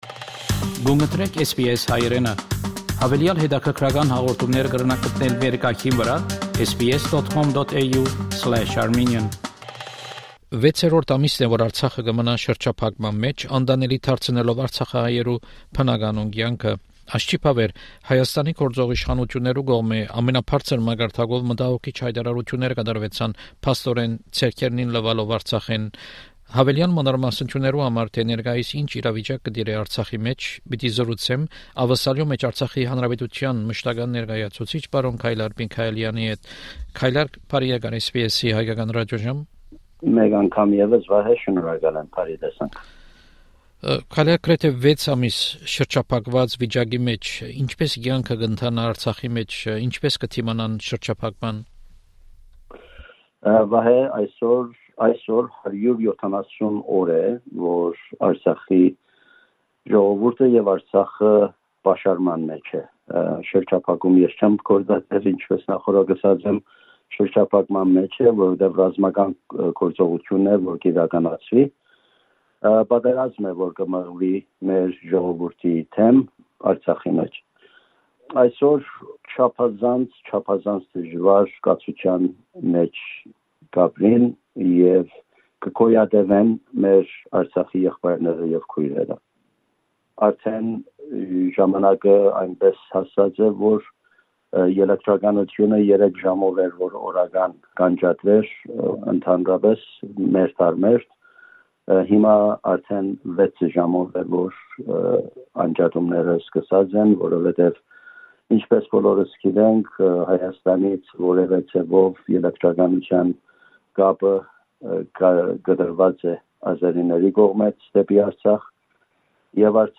Հարցազրոյց Աւստրալիոյ մէջ Արցախի Հանրապետութեան մշտական ներկայացուցիչ Պարոն Քայլար Միքայելեանի հետ: Հարցազրոյցի գլխաւոր նիւթն է Արցախի շարունակուող պաշարումը, աղէտալի հետեւանքները և ՀՀ իշխանութիւններու անընդունելի յայտարարութիւնները: